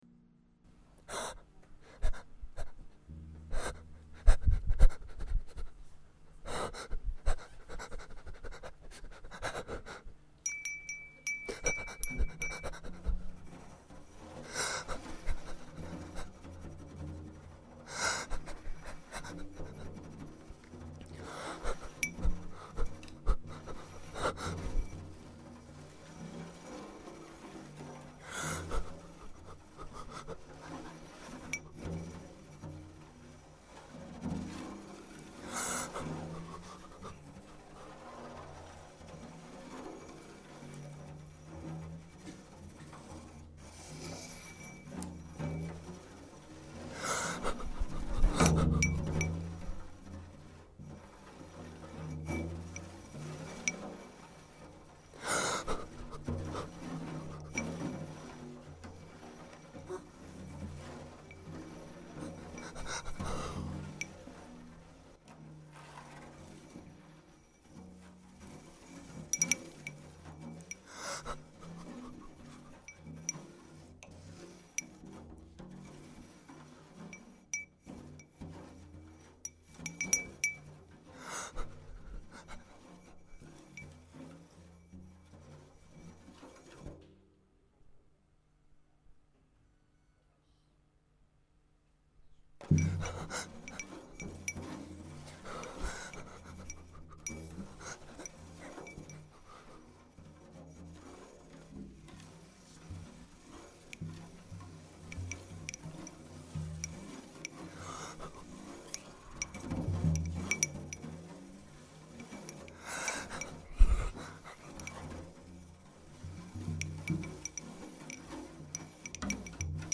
une série de concerts au casque de une demie-heure chaucun
trombone
à la clarinette et aux percussions
instrumentarium indéfinissable
stetienneimpro_mix.mp3